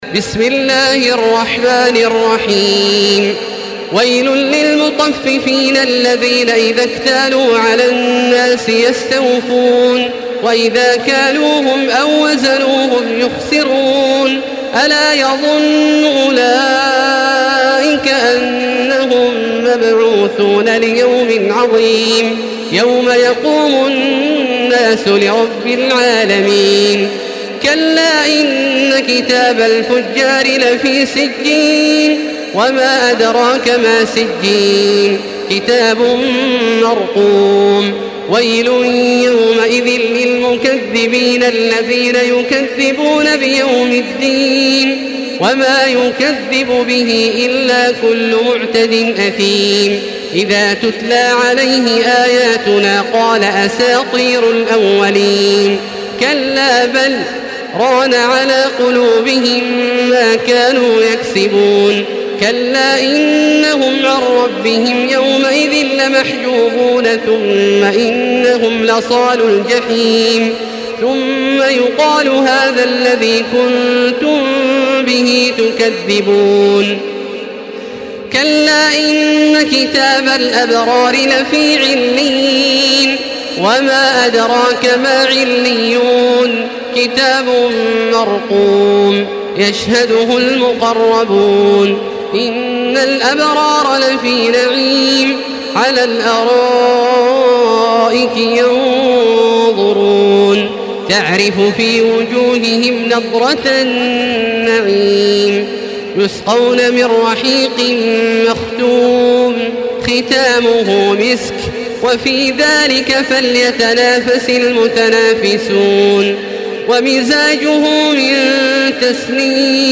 Surah المطففين MP3 by تراويح الحرم المكي 1435 in حفص عن عاصم narration.
مرتل